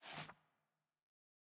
sfx_ui_map_panel_disappear.ogg